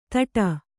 ♪ taṭa